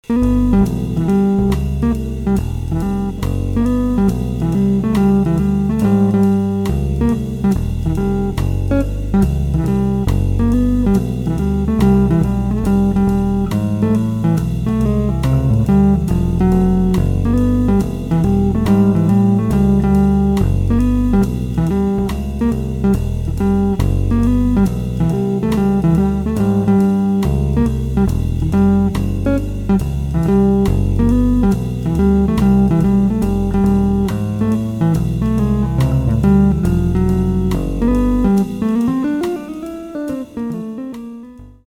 Chapman Stick Main  /\
drum loop
over walking bass: